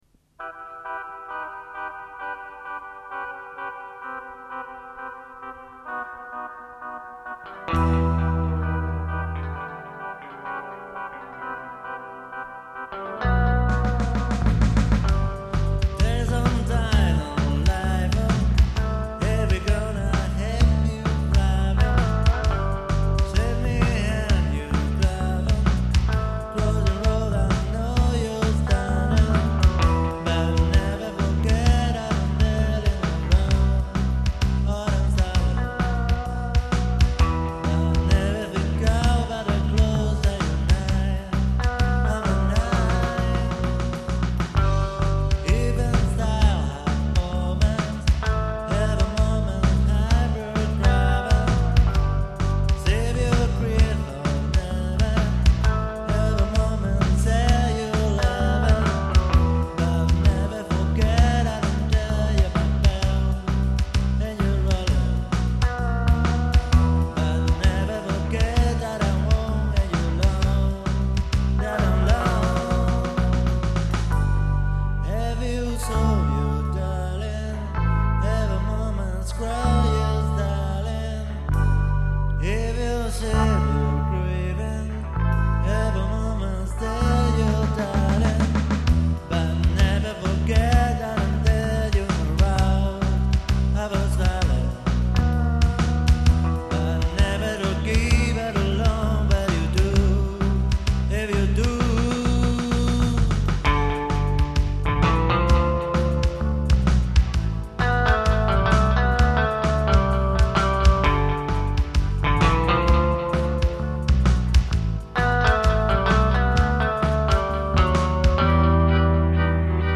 – el sonido es malo y temblón porque el original, una cinta de metal, se ha perdido y este .mp3 que escuchas está extraído de una copia.
– toda la grabación la hice con unos monitores de directo que no reflejaban para nada lo que después se iba a oír y en toda la «producción» faltan medios a toneladas pero como yo por aquél entonces no tenía (ni soñaba con tener) un ecualizador gráfico de porrocientas bandas, lo arreglé como pude moviendo graves y agudos pero no suena, para nada, como sonaba mientras lo grababa.
Son casi tres minutos utilizando fonemas ingleses sin dar ni una a derechas, casi roza el virtuosismo.
Esta canción está en puro guachiflay así que la letra queda a la imaginación del escuchante.